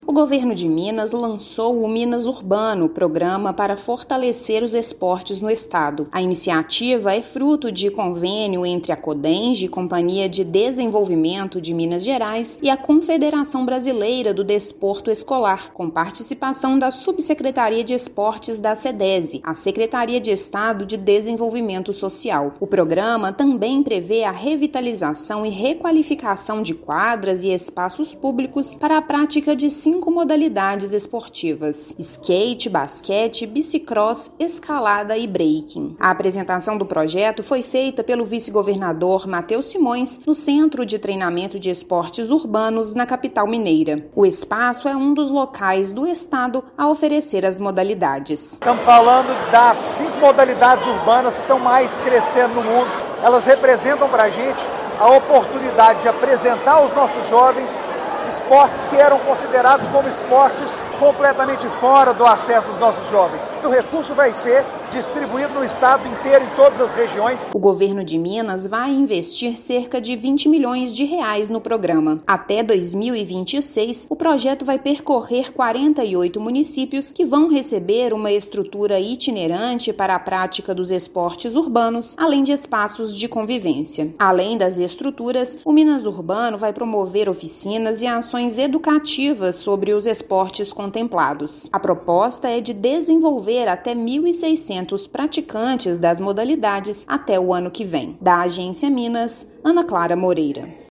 Minas Urbano vai incentivar ainda a prática de BMX (bicicross), escalada e breaking, gerando mais oportunidades para os jovens. Ouça matéria de rádio.